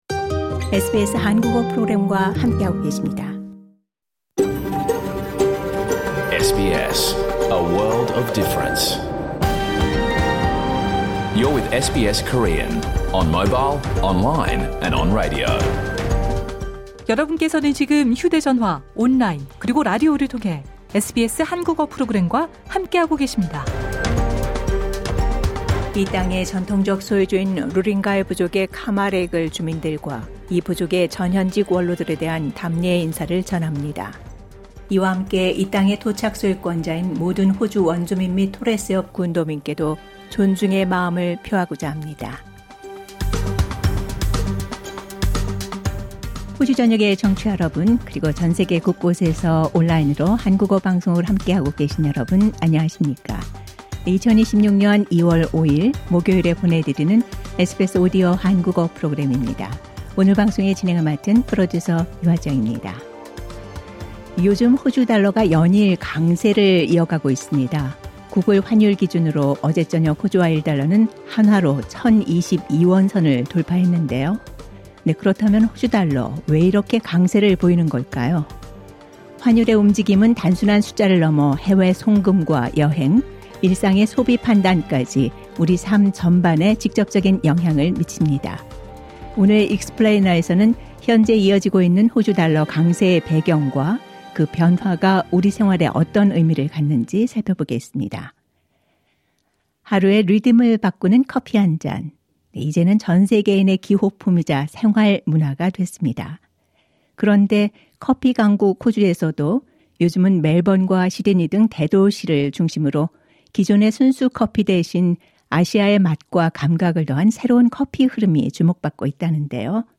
2026년 2월 5일 목요일에 방송된 SBS 한국어 프로그램 전체를 들으실 수 있습니다.